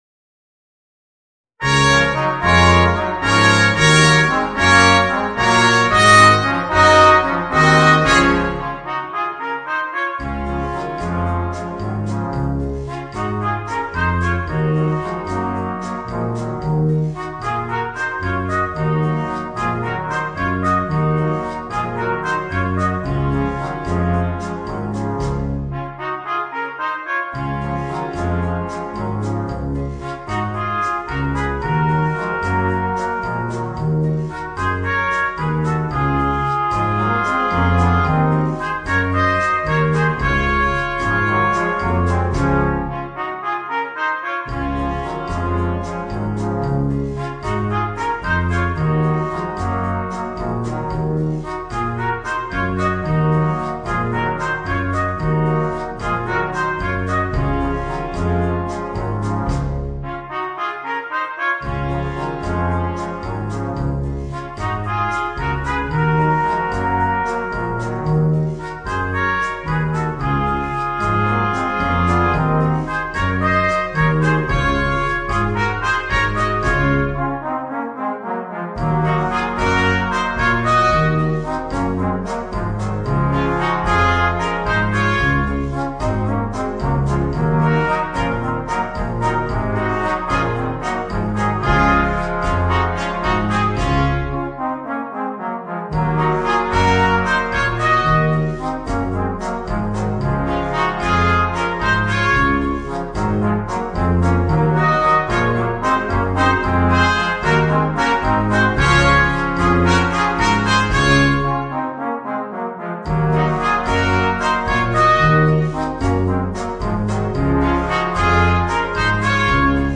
Voicing: 2 Trumpets, Trombone, Euphonium and Tuba